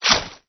WAV · 21 KB · 單聲道 (1ch)